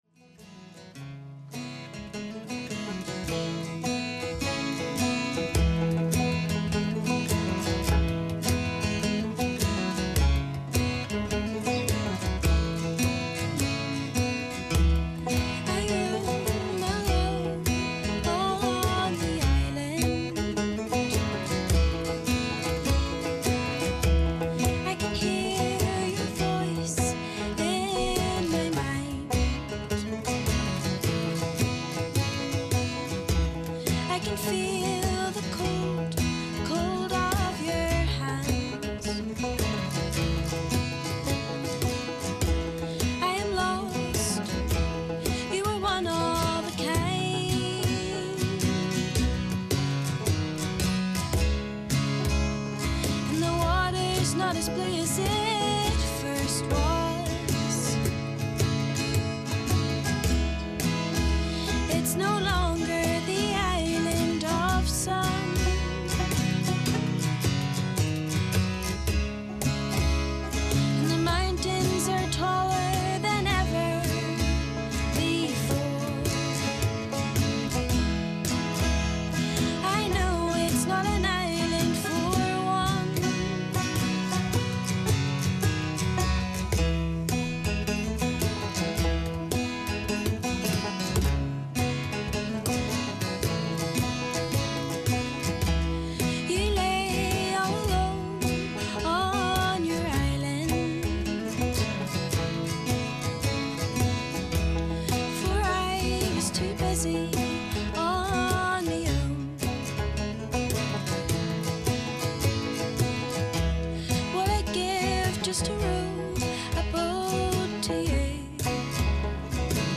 Ruaille Buaille le Fusa Beo sa Stiúideo